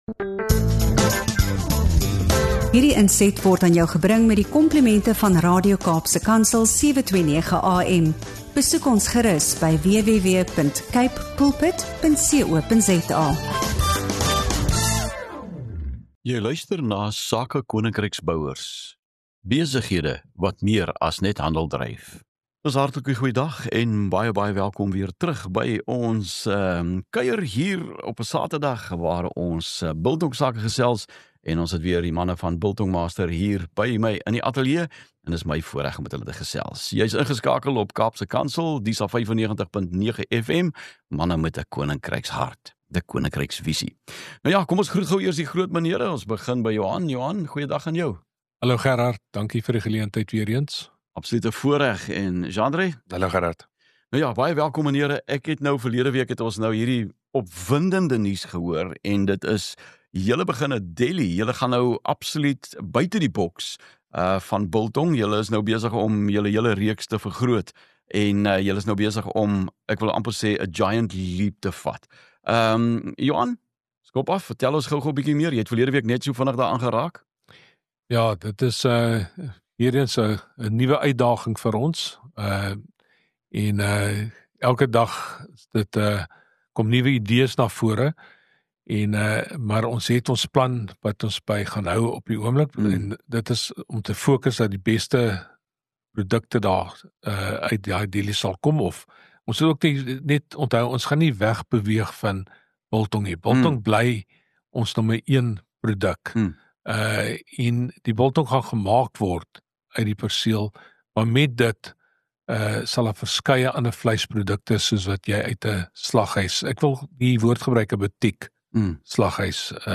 Hierdie episode fokus op vakmanskap, kliëntediens, innovasie en geloofswaardes, en hoe ’n biltongwinkel meer kan wees as net ’n plek om vleis te koop – maar ’n ervaring waar mense welkom voel en kwaliteit eerste kom. 🎧 Luister saam na ’n eerlike gesprek oor groei, passie, tradisie en hoe om iets anders te doen as die gewone slaghuis of deli.